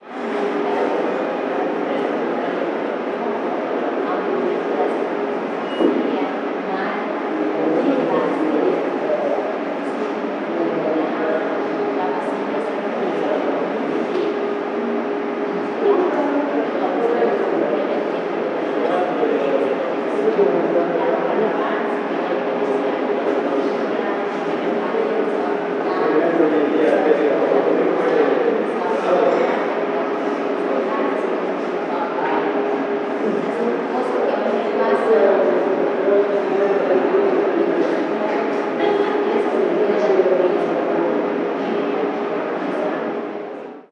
Ambiente de gente en la estación del Ave